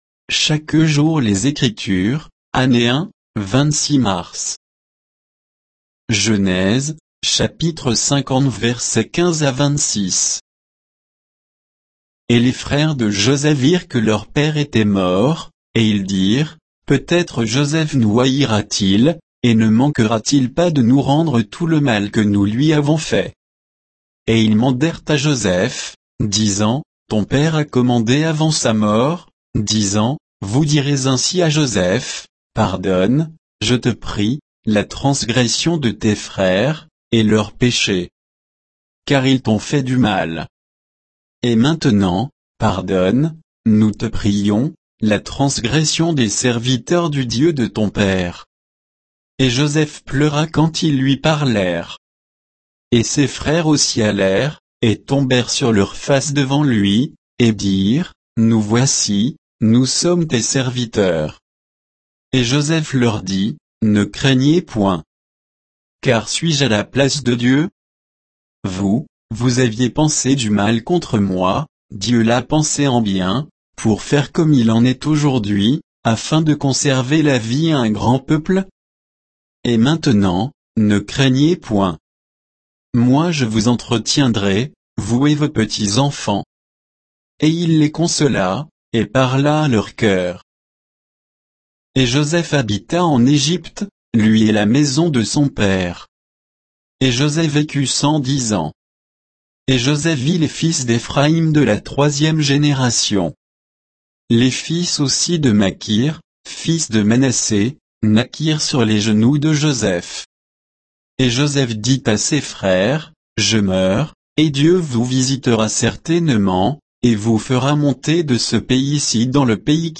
Méditation quoditienne de Chaque jour les Écritures sur Genèse 50, 15 à 26